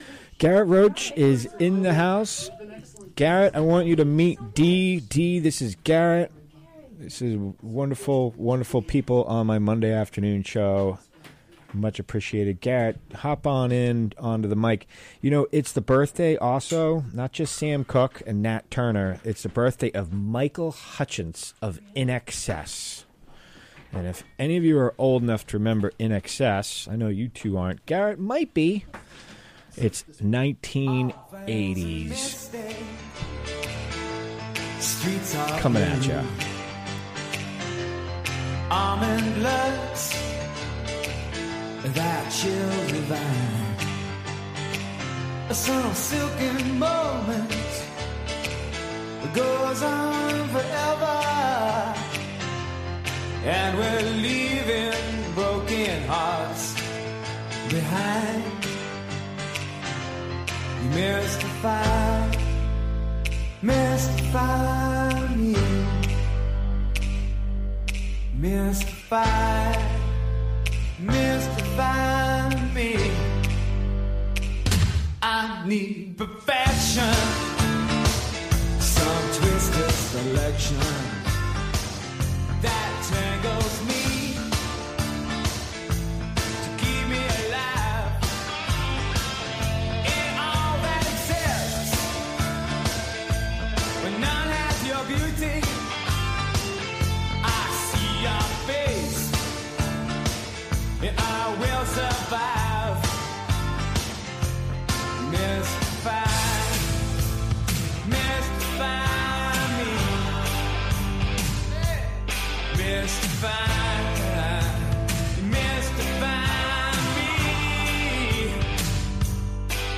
Recorded during the WGXC Afternoon Show Monday, January 22, 2018.